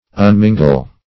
Search Result for " unmingle" : The Collaborative International Dictionary of English v.0.48: Unmingle \Un*min"gle\, v. t. [1st pref. un- + mingle.]